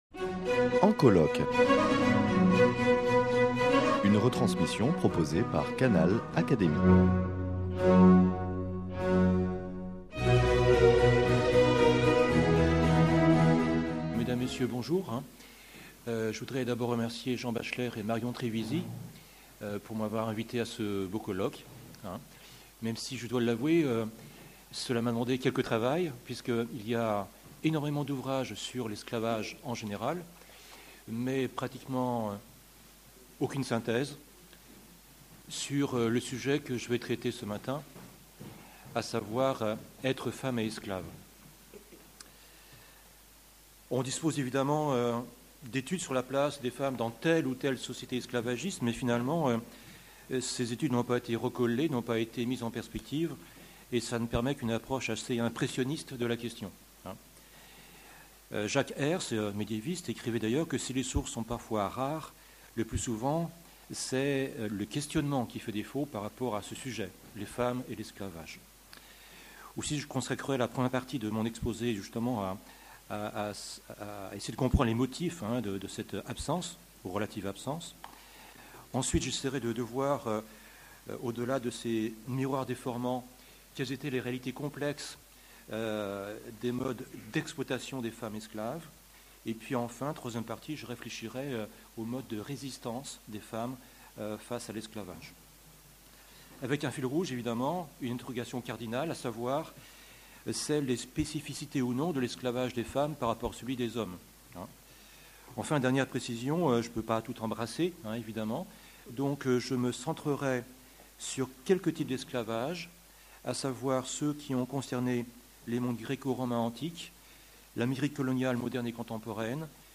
prononcée le 14 janvier 2016 lors des journées d’étude « La guerre et les femmes »